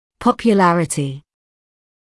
[ˌpɔpju’lærətɪ][ˌпопйу’лэрэти]популярность, известьность